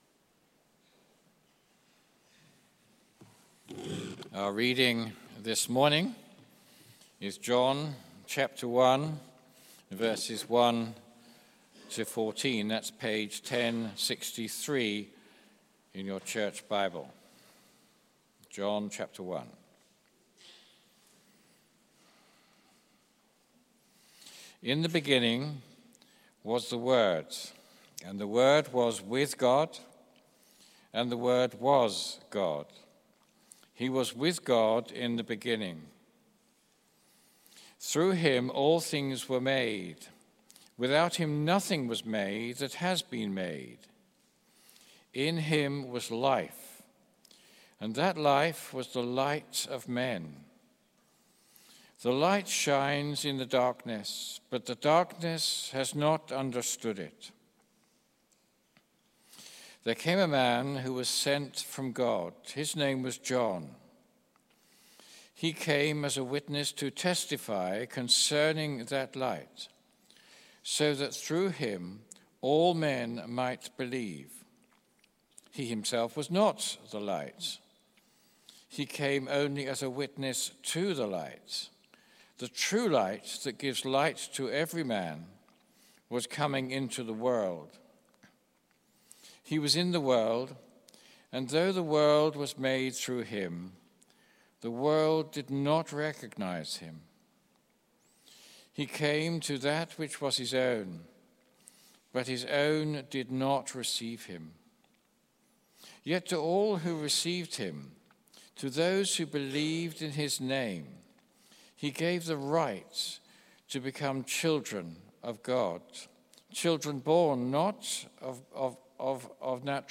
Series: Christmas with John: Light Has Come Theme: The Word Dwells Sermon